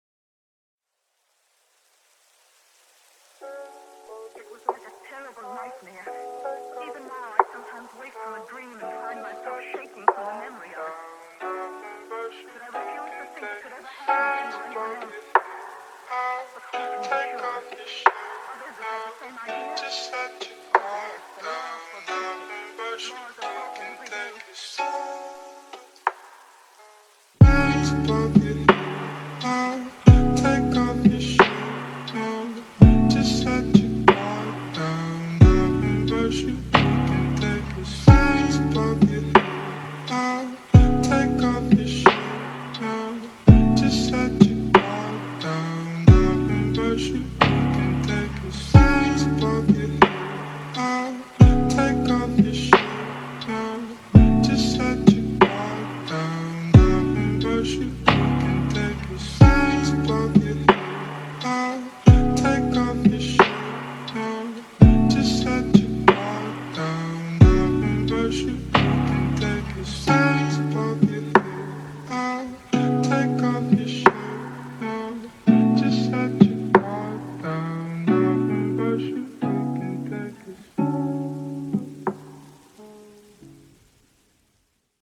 The production is sleek yet organic